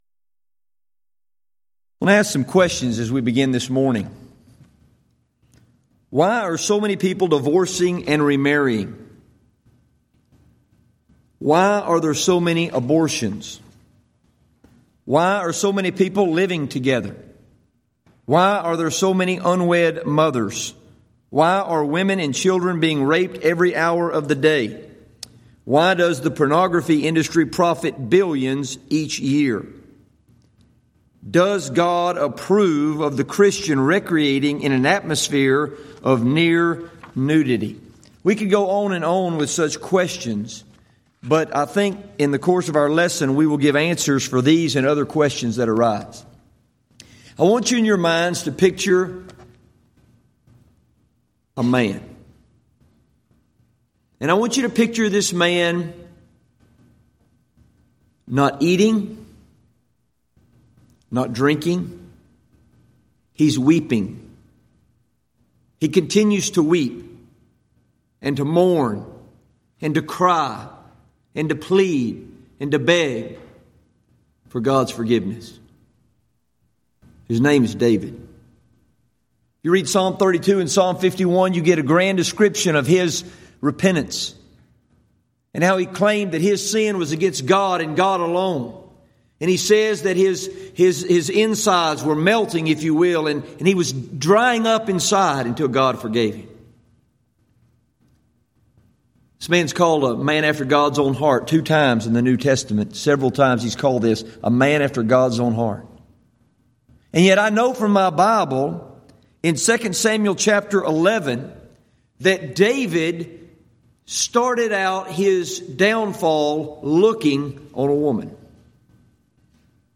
Event: 5th Annual Back to the Bible Lectures Theme/Title: Moral Issues Facing Our Nation and the Lord's Church